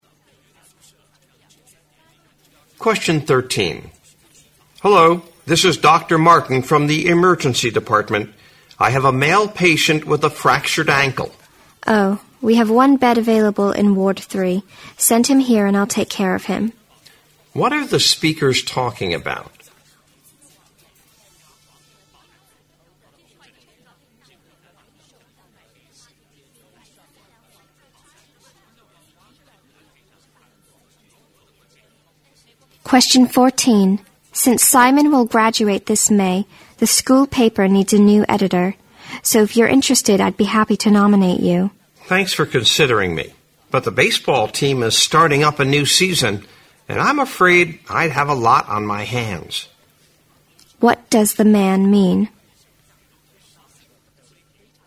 新编六级听力短对话每日2题第20期